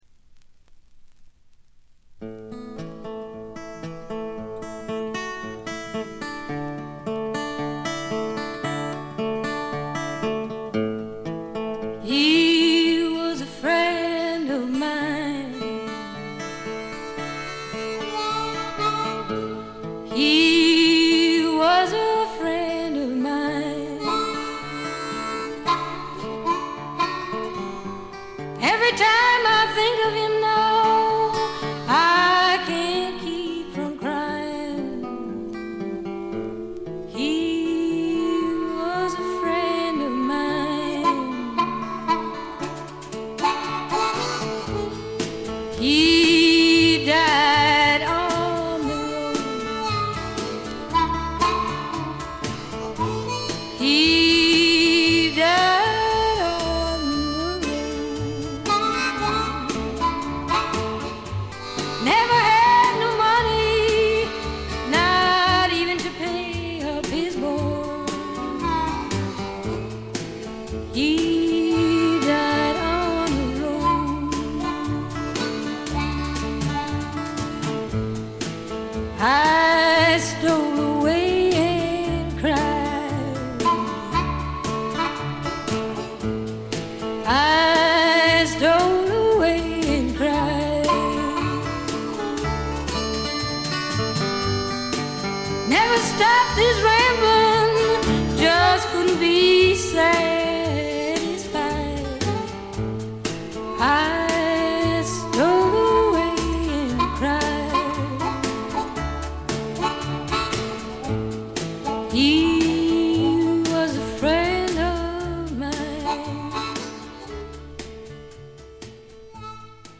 微細なノイズ感のみ。
ここでの彼女はみずからギターを弾きながら歌う渋い女性ブルースシンガーという側面を見せてくれます。
激渋アコースティック・ブルースにオールドジャズやR&Bのアレンジが施されたサウンド作りもいい感じですね。
試聴曲は現品からの取り込み音源です。